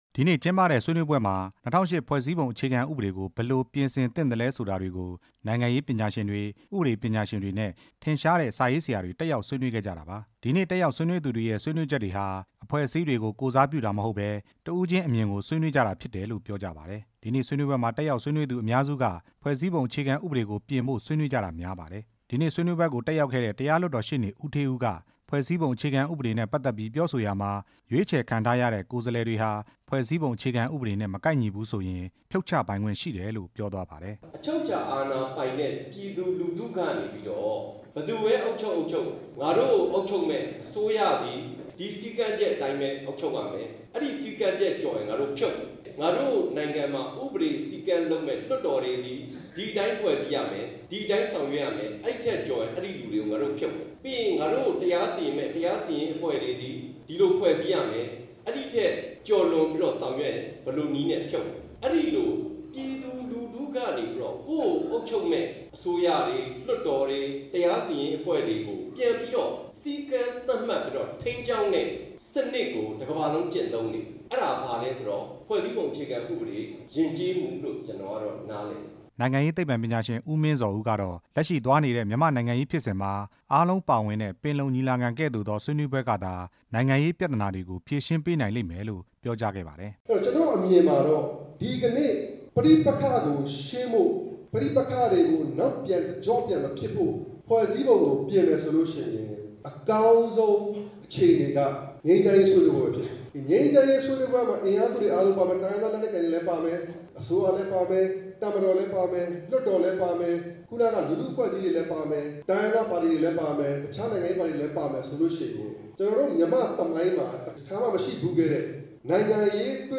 ဆွေနွေးပွဲအကြောင်း တင်ပြချက်